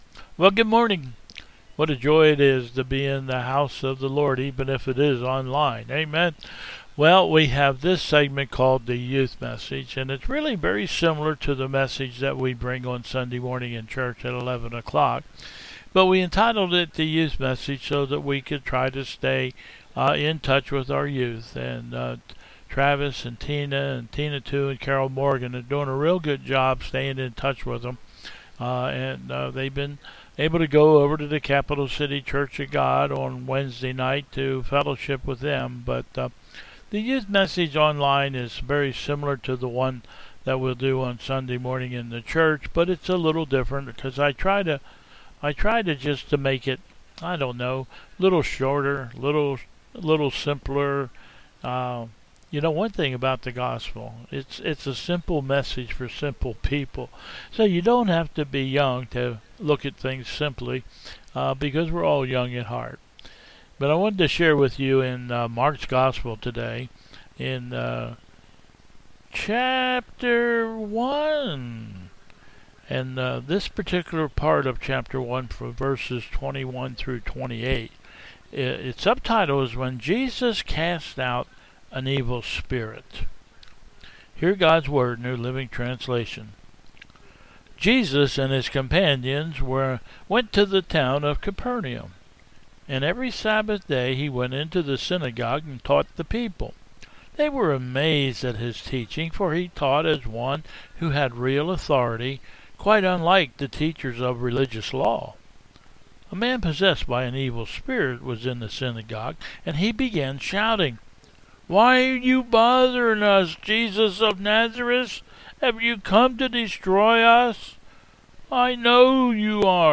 Bethel 01/31/21 Service
Processional